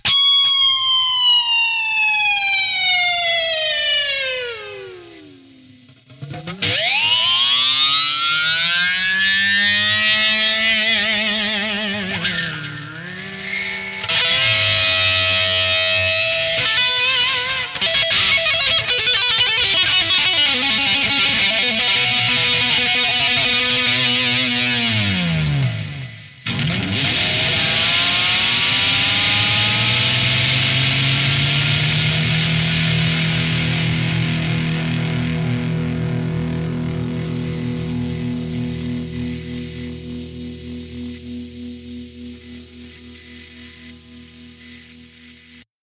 • Play the guitar sloppily
• Tremolo bar play like int the 80s (^ ^);
arming_sample01.mp3